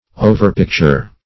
Search Result for " overpicture" : The Collaborative International Dictionary of English v.0.48: Overpicture \O`ver*pic"ture\, v. t. To surpass nature in the picture or representation of.